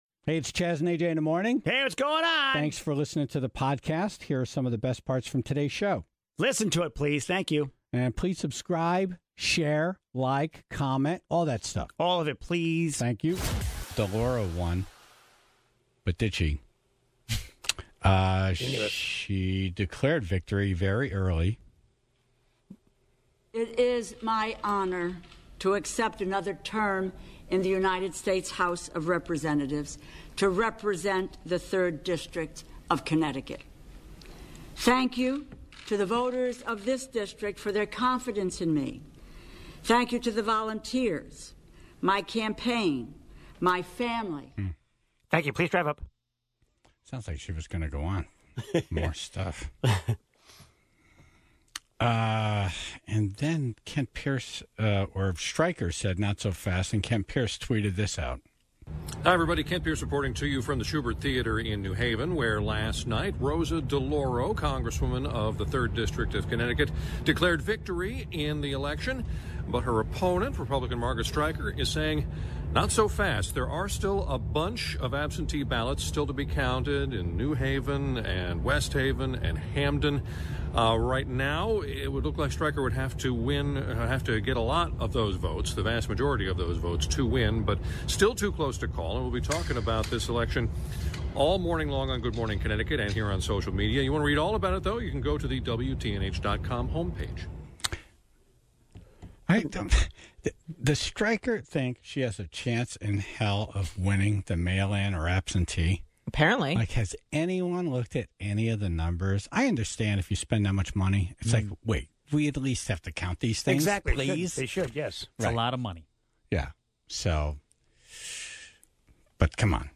(28:12) Mayor Boughton of Danbury was on to talk about the election results, and why he thinks running for Governor of Connecticut is not something he'll pursue again.